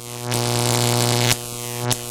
an electric fence.ogg